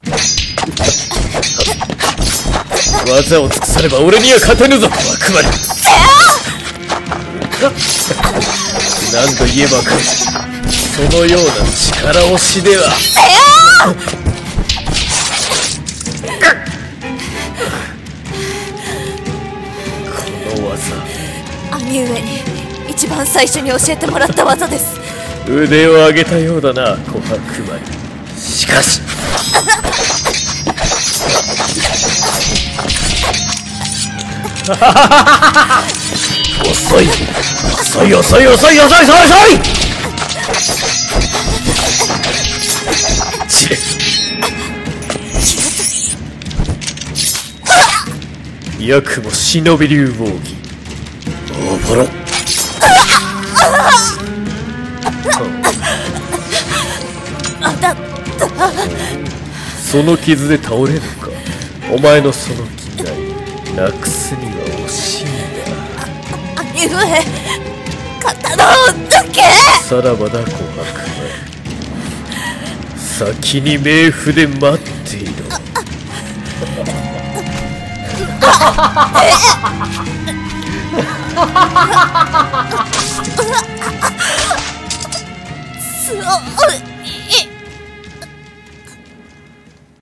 【和風声劇】業火の決別(下【忍者/戦闘/台本】